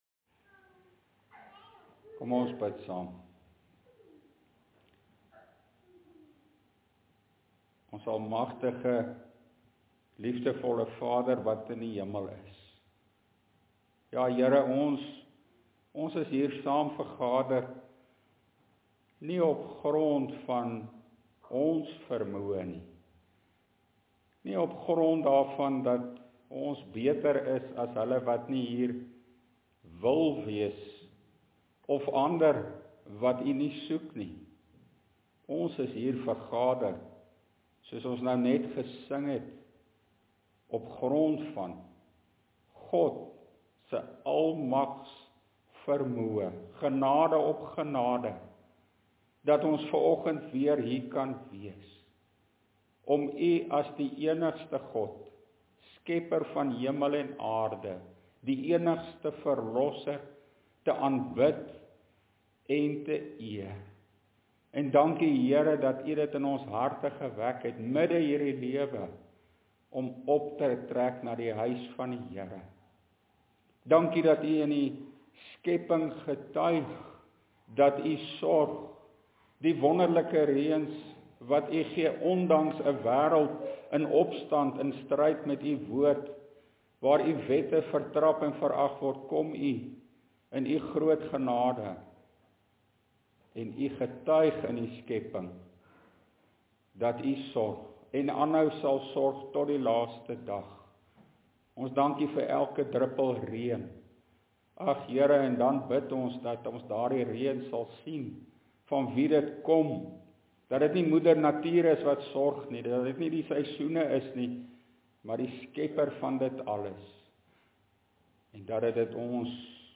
LEERPREDIKING: NGB artikel 28 en 29